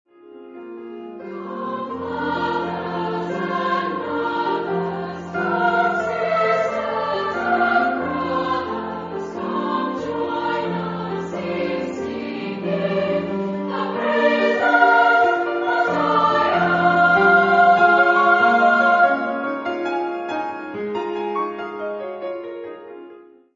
Revivalist Song
Genre-Style-Forme : Chanson des états du Sud ; Arrangement
Type de choeur : SATB  (4 voix mixtes )
Instruments : Piano (1)
Tonalité : fa majeur